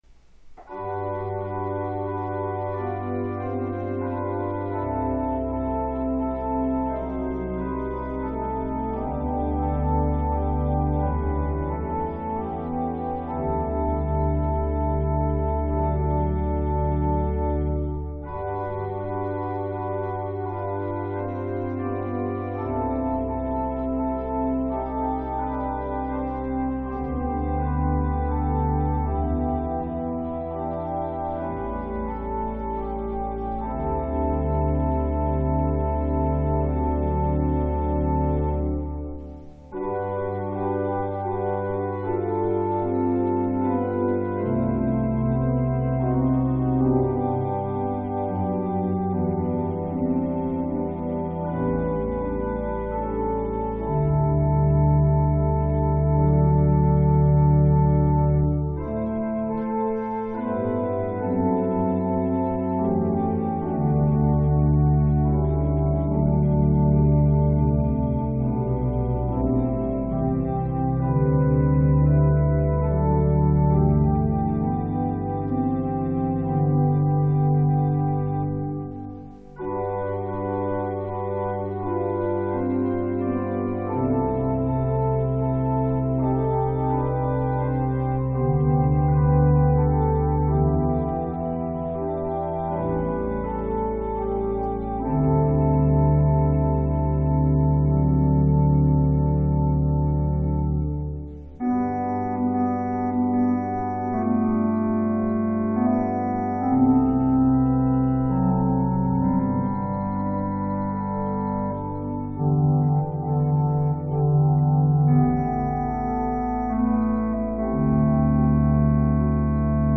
Jesus, the Very Thought of Thee – Organ — pdf